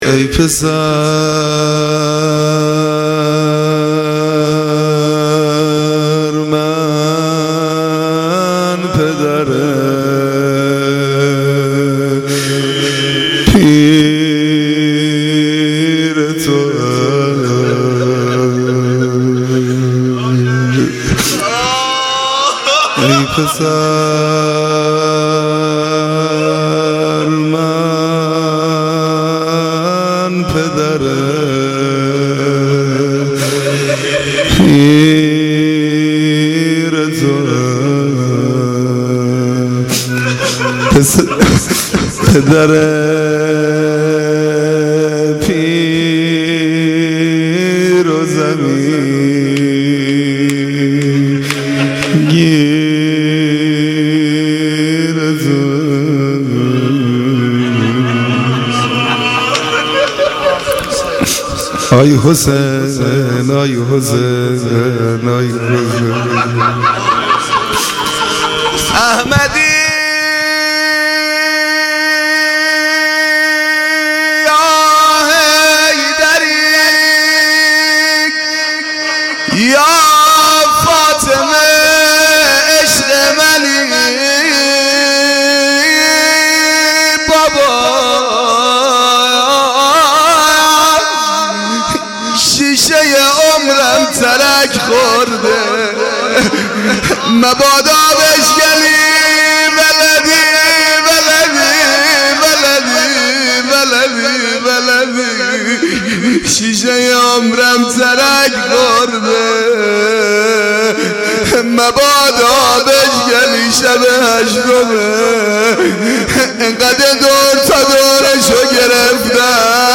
مداحی
Shab-8-Moharam-03.mp3